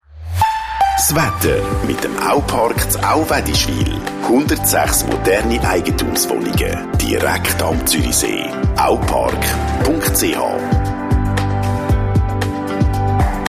Radiospot-Sponsoring Wetter (Aupark) – 13 Sekunden
SPONS-Wetter-November-2025.mp3